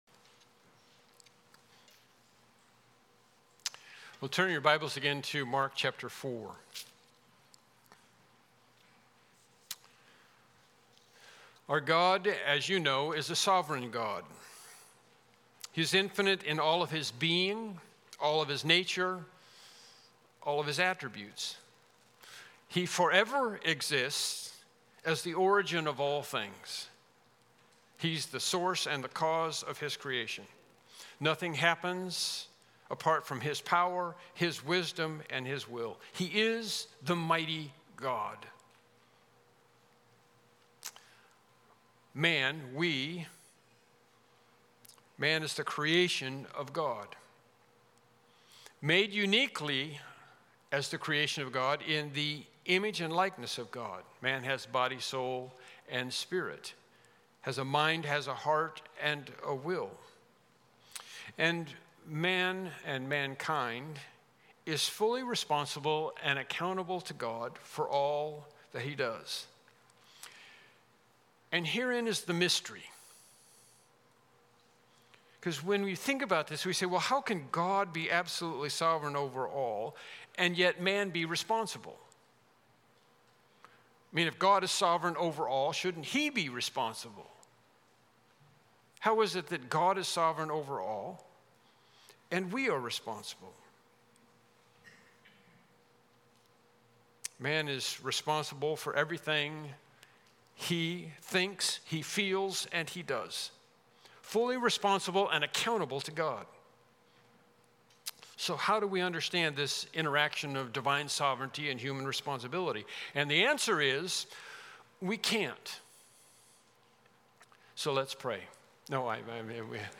Passage: Mark 4:10-13 Service Type: Morning Worship Service